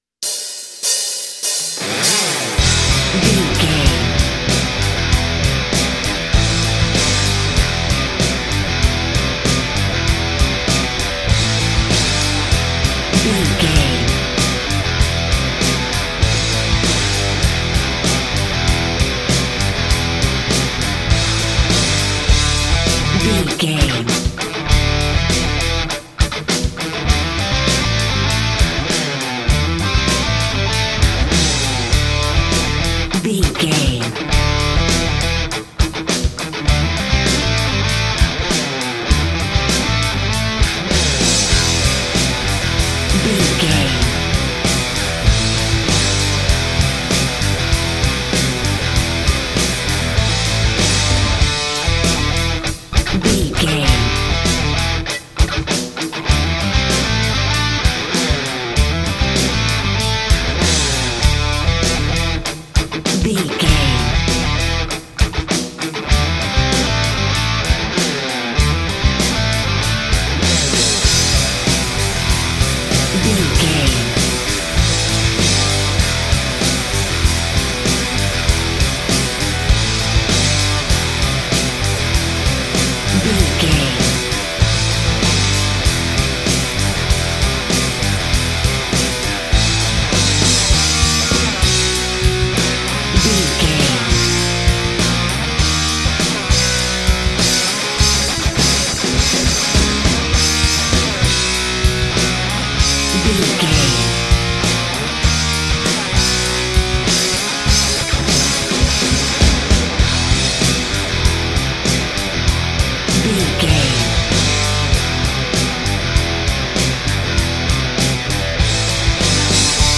Aeolian/Minor
drums
electric guitar
Sports Rock
hard rock
fast action
lead guitar
bass
aggressive
energetic
intense
nu metal
alternative metal